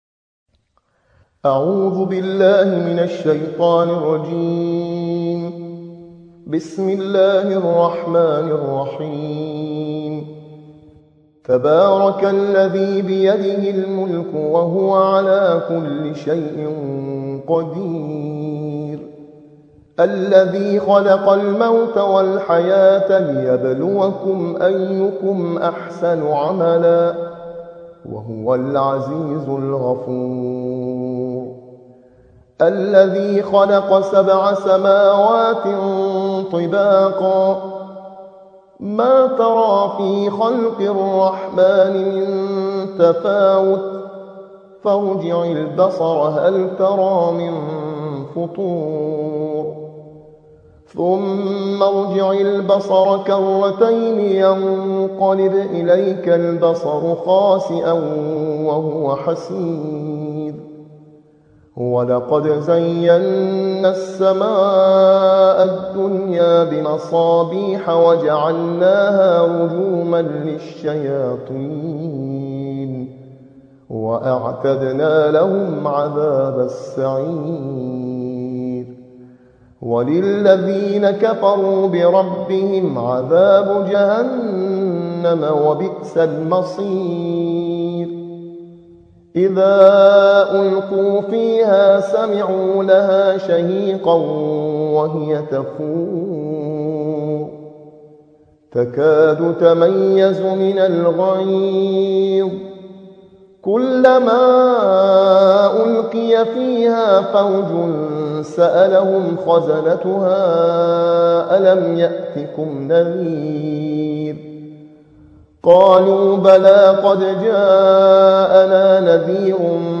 ترتیل‌خوانی جزء 29 و 30 قرآن